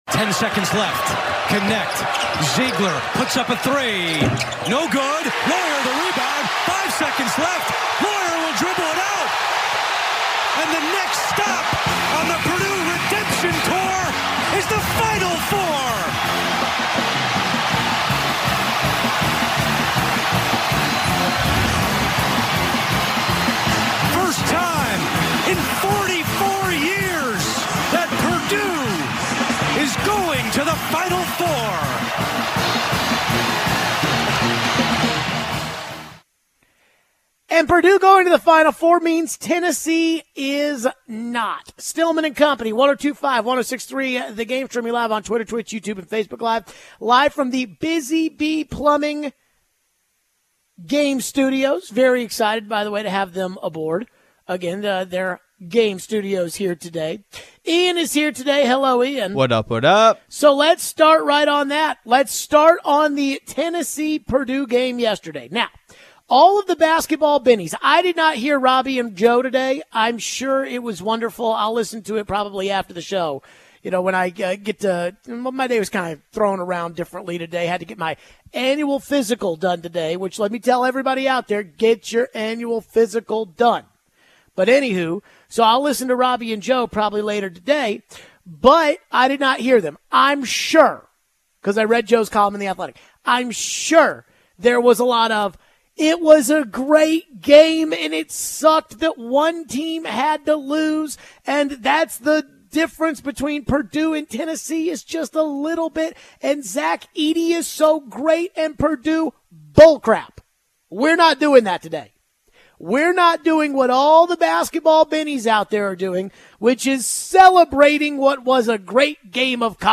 Should the Vols have been more proactive in calling a timeout in the 1st half run by Purdue? We take your phones.